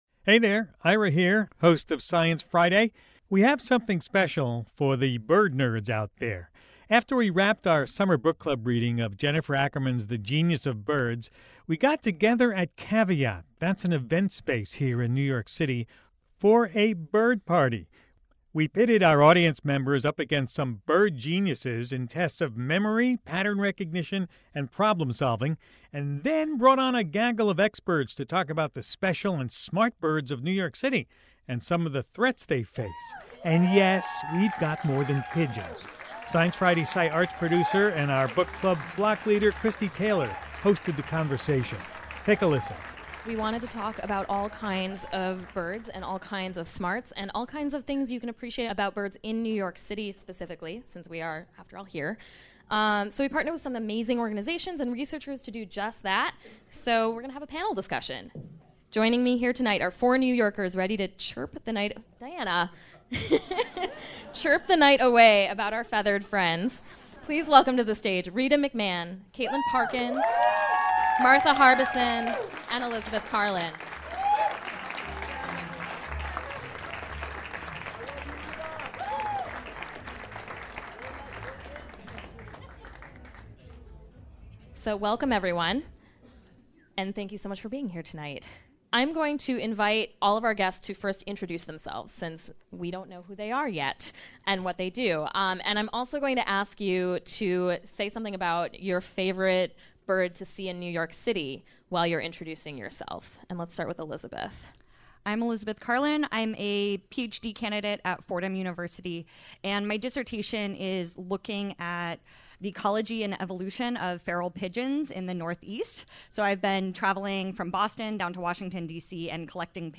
But after wrapping up our summer discussion of Jennifer Ackerman’s The Genius of Birds, bird enthusiasts flocked together at Caveat, a venue in New York City, for one last celebration of bird brains and feathered phenomena.
We pitted audience members up against some local bird geniuses in tests of memory, pattern recognition, and problem-solving. Then, we brought on a gaggle of experts to talk about the special and smart birds of New York City, along with some of the threats they face—including bright lights and deceptive glass.